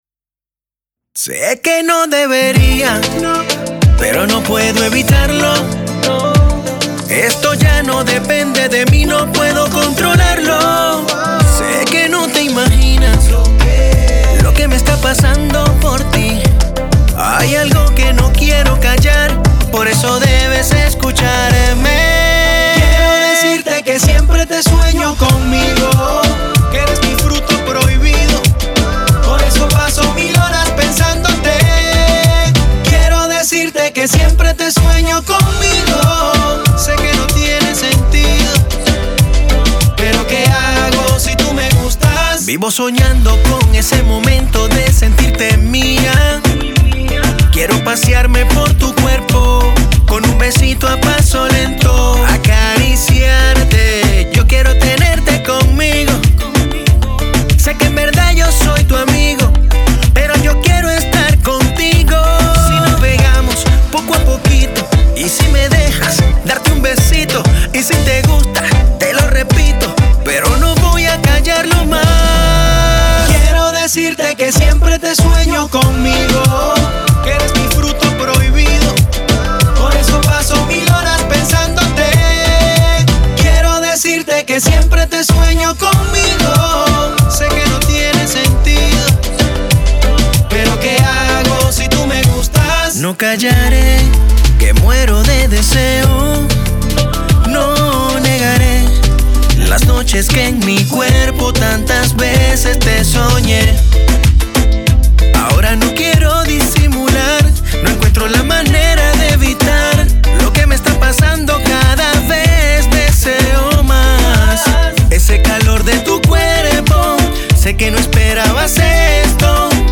letras románticas en medio de coros pegajosos